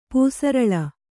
♪ pūsaraḷa